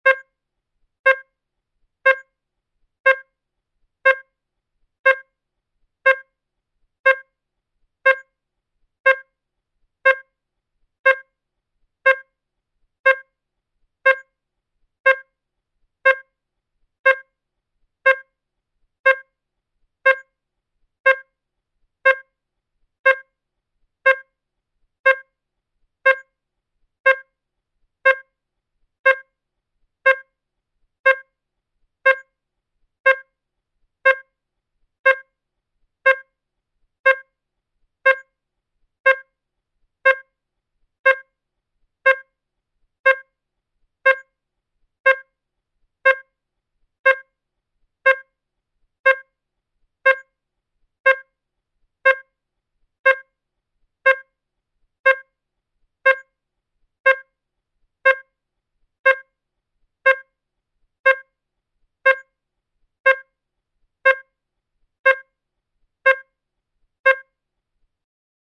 Hospital Monit Machine Beep